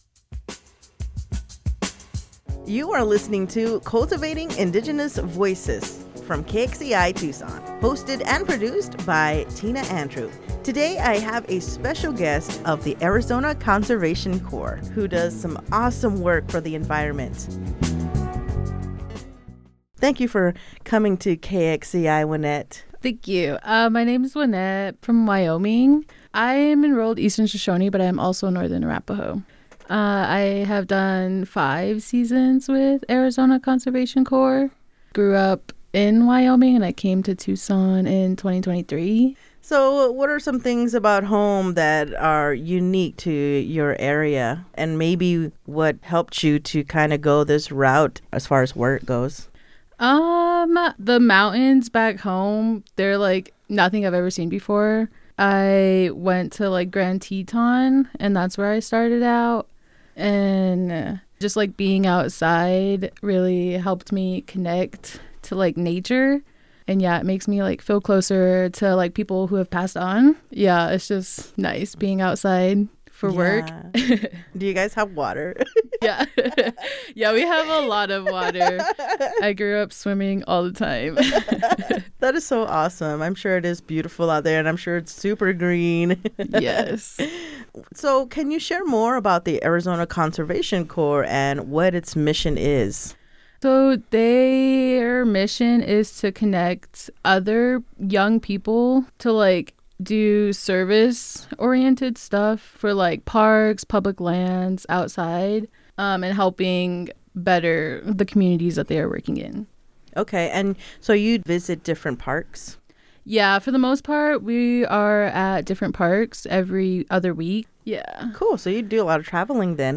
Here is a brief version of the interview that offers key highlights and insights from the full conversation.